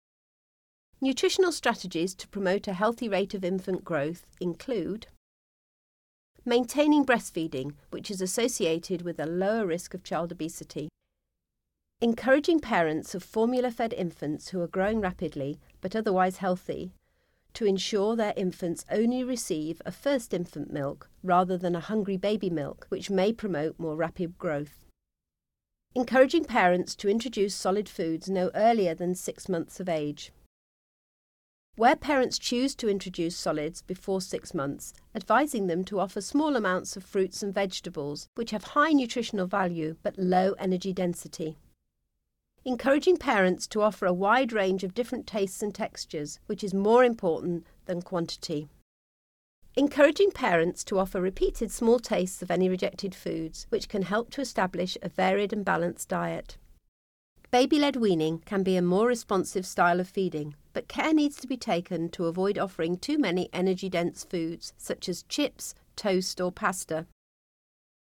Narration audio (OGG)